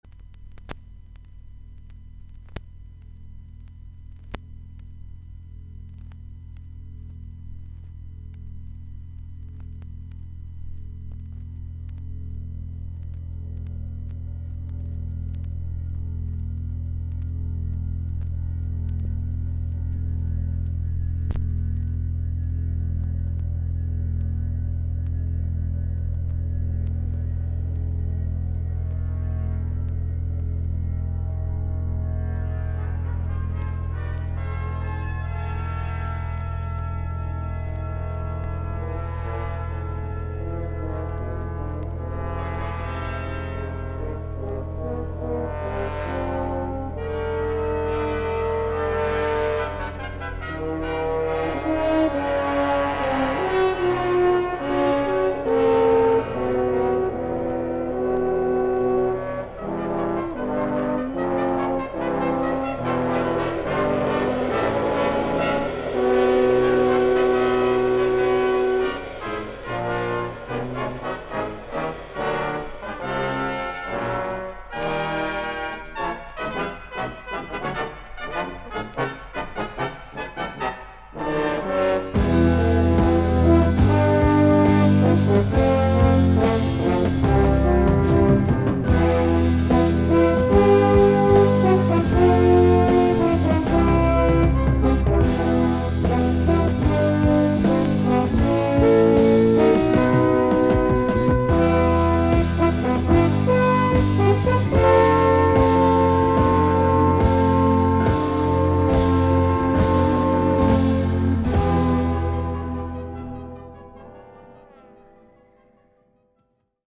A1最初の無録音部分からフェードインのイントロ部分に3回パチノイズあり。
ほかはVG+〜VG++:少々軽いパチノイズの箇所あり。少々サーフィス・ノイズあり。クリアな音です。